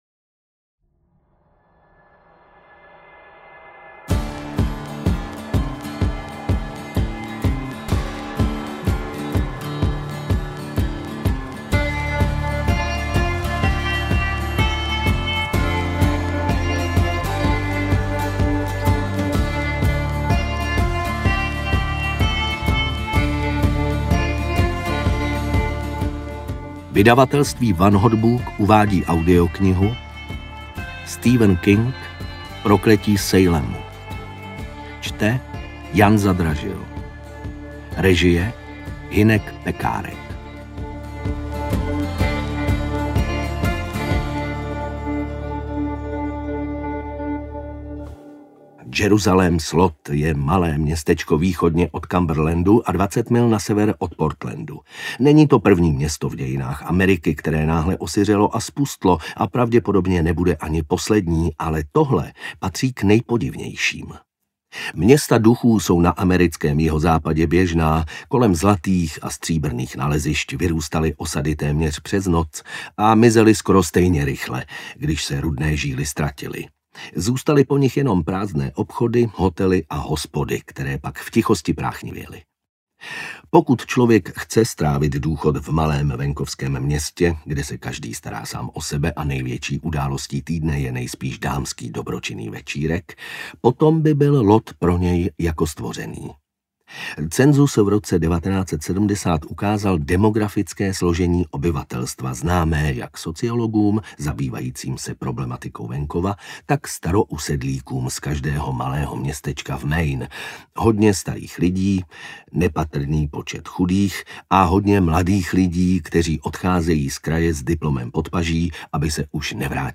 Prokletí Salemu audiokniha
Ukázka z knihy